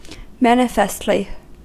Ääntäminen
Synonyymit obviously Ääntäminen US Haettu sana löytyi näillä lähdekielillä: englanti Käännöksiä ei löytynyt valitulle kohdekielelle. Määritelmät Adverbit In a manifest manner; obviously .